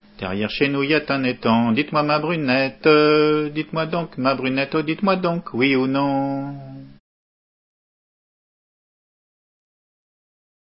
ROND DE LOUDEAC
Entendu au festival des "Assemblées gallèses" en juillet 91